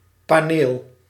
Ääntäminen
US : IPA : [ˈpæn.əl]